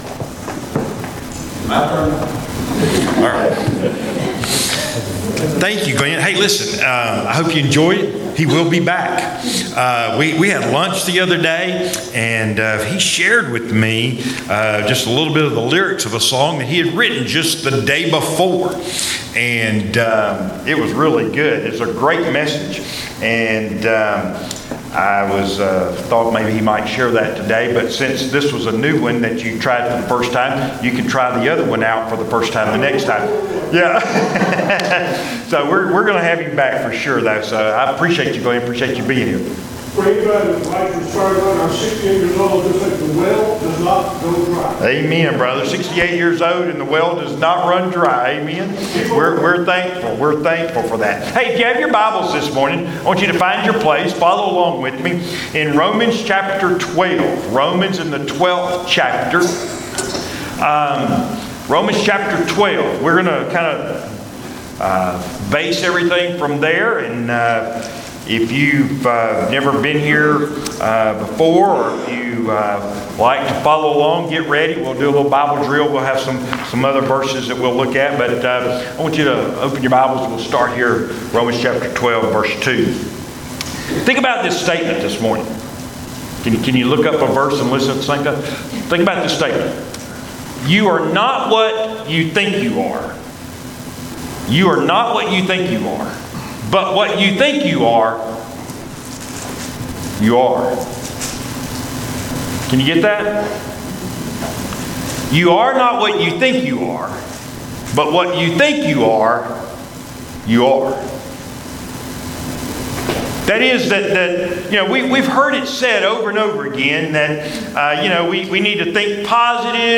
Audio Sermons: Videos of service can be seen on Facebook page | Trinity Baptist Church